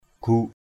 /ɡ͡ɣuʔ/ 1. (đg.) trì xuống = tirer du haut vers le bas. pan di dhan guk trun pN d} DN g~K t~N nắm cành cây trì xuống. guk akaok trun...